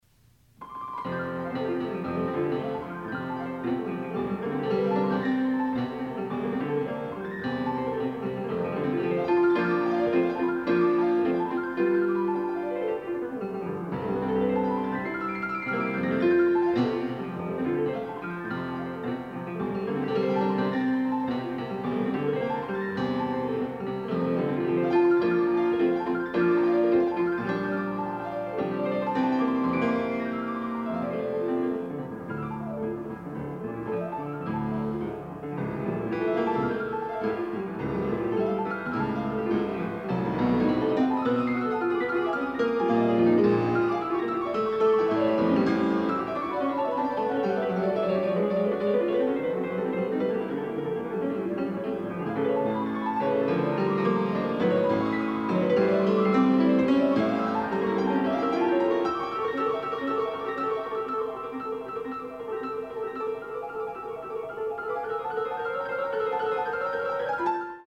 Soloist
Recorded September 11, 1977 in the Ed Landreth Hall, Texas Christian University, Fort Worth, Texas
Sonatas (Piano)
Suites (Piano)
performed music